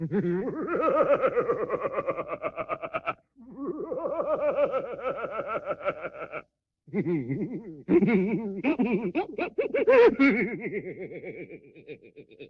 File:Evil Sinister Laughing.oga
Evil_Sinister_Laughing.oga.mp3